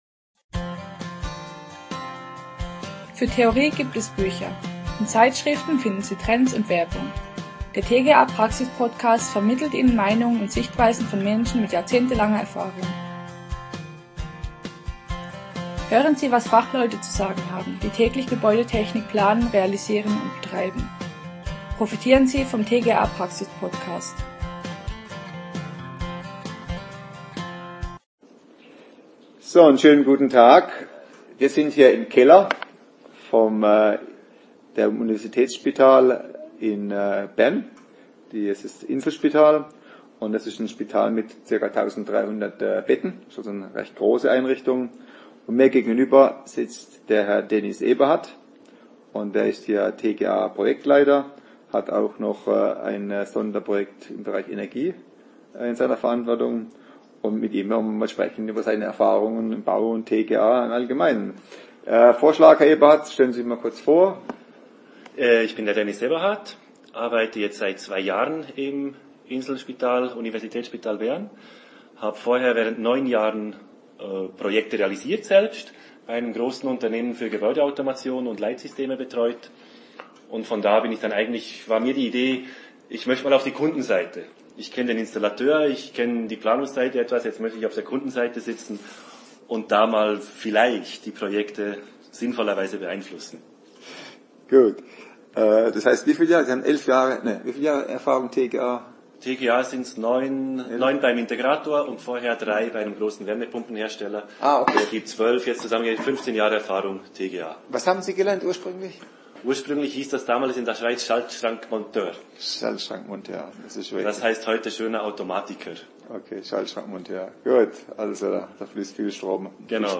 Erfahrene und renommierte TGA Fachleute sprechen über ihre Erfahrungen in der heutigen Baukultur. Schonungslose, ehrliche Interviews über TGA Praxis.